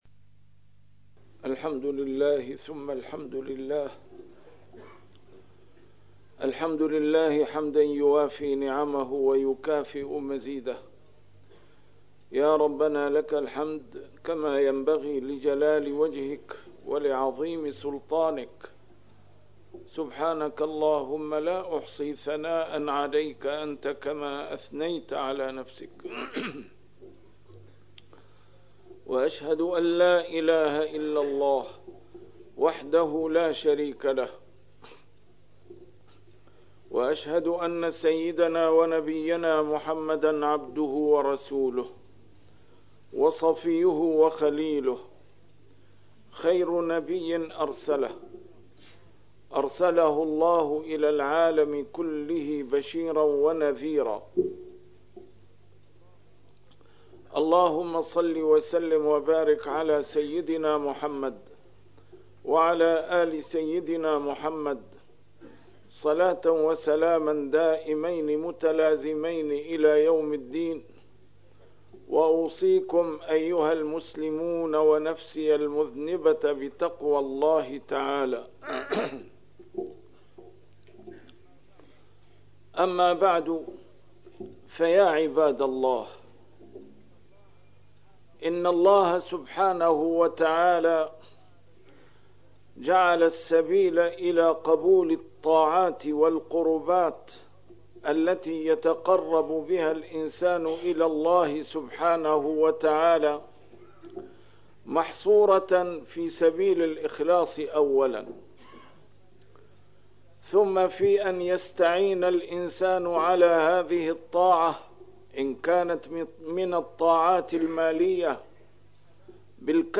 A MARTYR SCHOLAR: IMAM MUHAMMAD SAEED RAMADAN AL-BOUTI - الخطب - الحجاج المزيفون